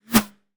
pgs/Assets/Audio/Guns_Weapons/Bullets/bullet_flyby_fast_08.wav at master
bullet_flyby_fast_08.wav